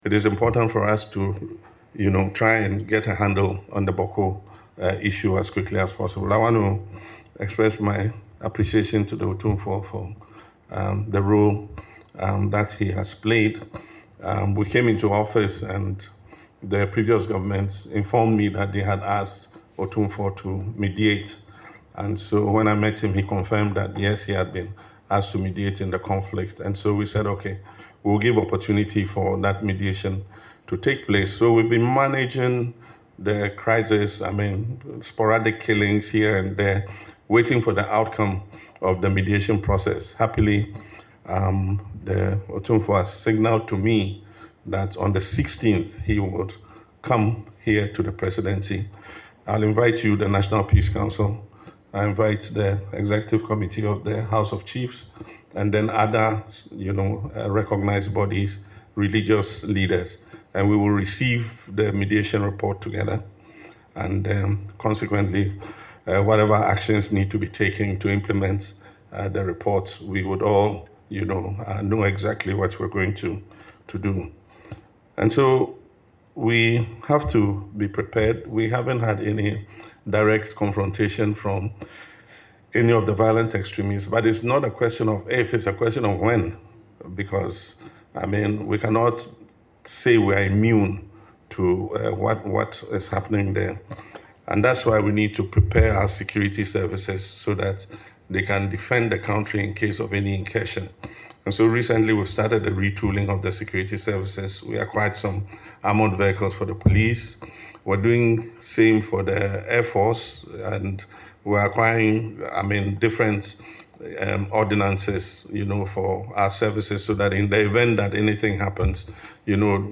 Speaking at the Presidency during an engagement with the National Peace Council on strengthening national unity, President Mahama underscored the urgency of addressing the Bawku conflict, especially given the area’s proximity to Sahelian countries facing terrorist threats.
LISTEN TO PRESIDENT MAHAMA IN THE AUDIO BELOW: